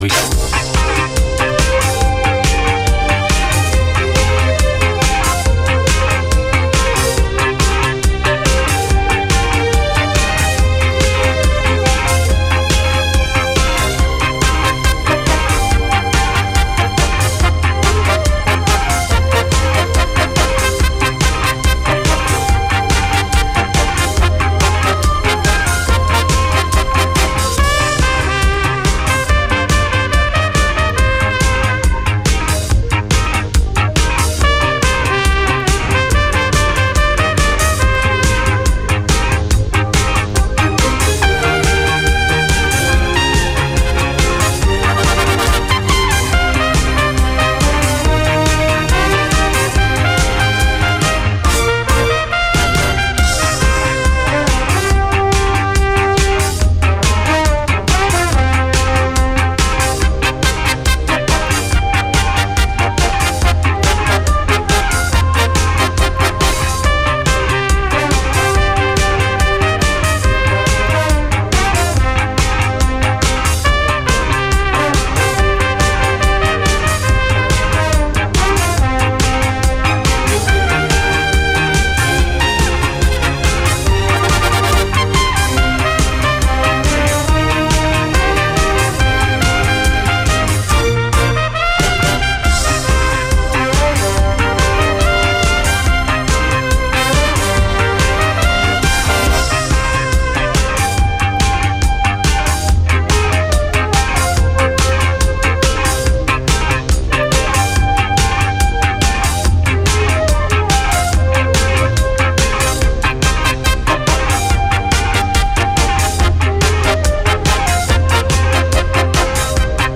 Запись 28.11.1983   ČSRo Bratislava
Труба